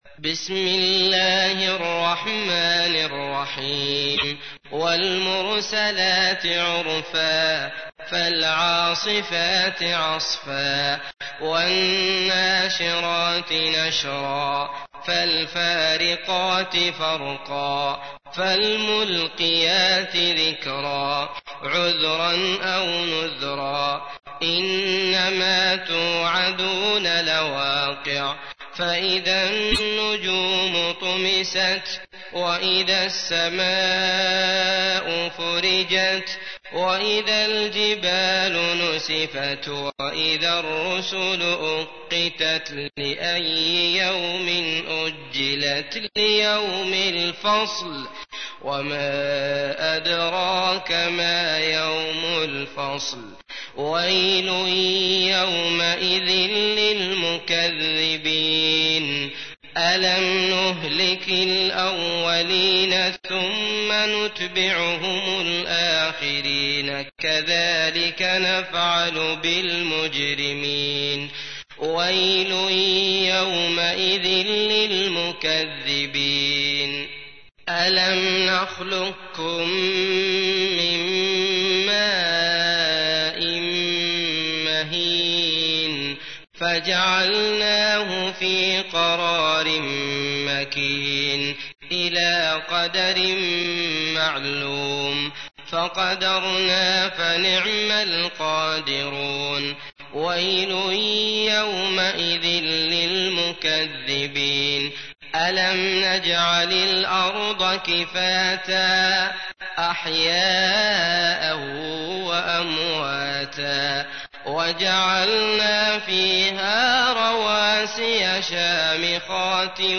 تحميل : 77. سورة المرسلات / القارئ عبد الله المطرود / القرآن الكريم / موقع يا حسين